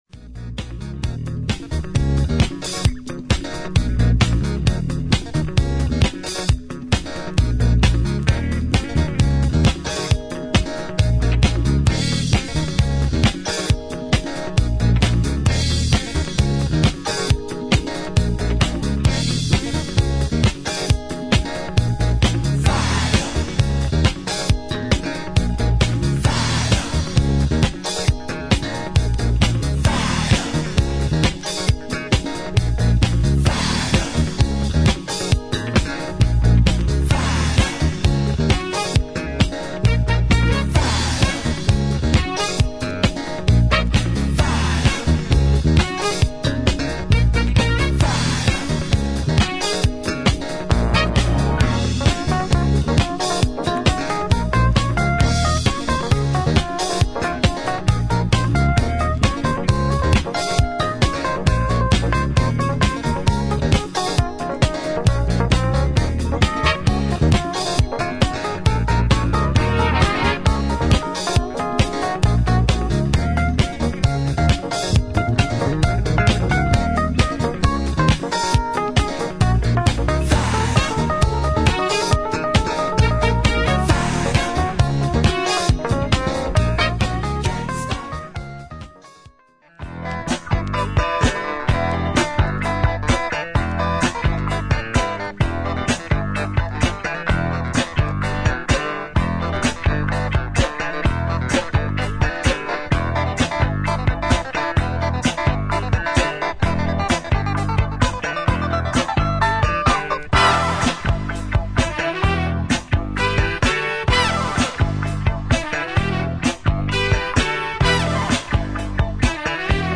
バージニア出身のディスコ/ファンク・バンド
ほっこりとした温もりのあるサウンドが気持ち良い
エレピやヴィブラフォン、ホーンセクションが織りなす軽やかなグルーヴが心地良い